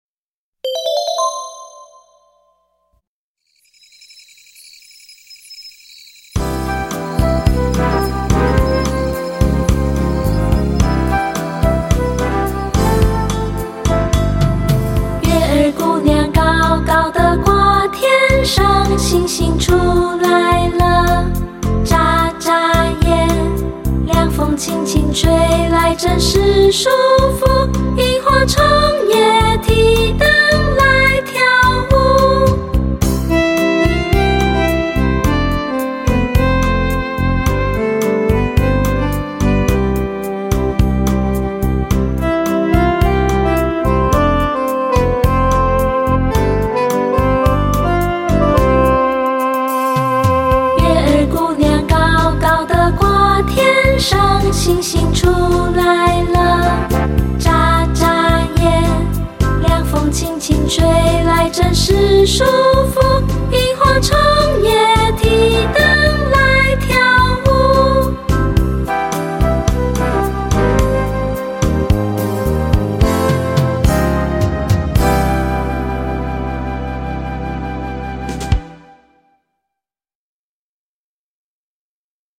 1下ch6夏夜（範唱）.mp3